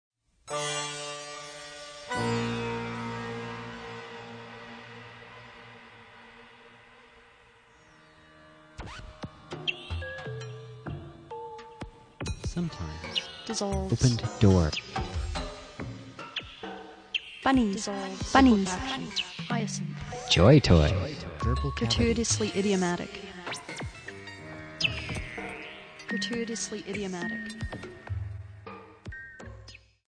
algorhythmically generated conversation where textual